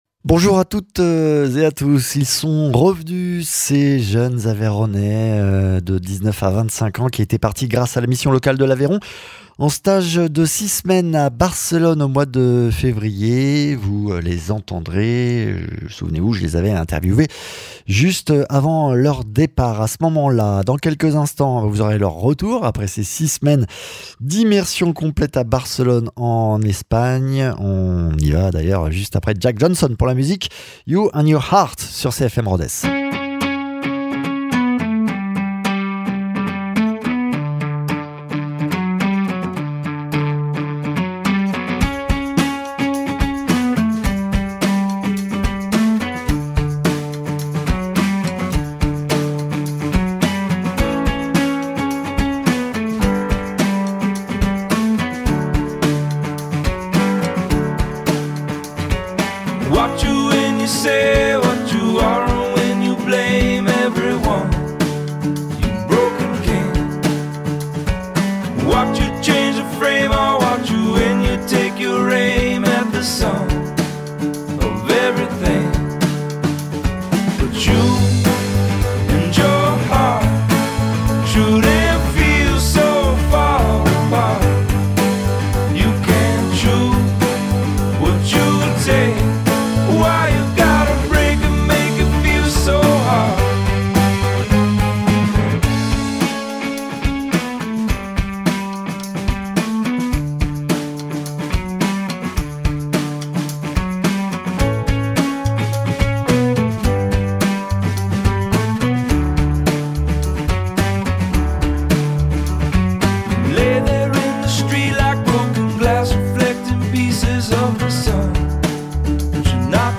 stagiaires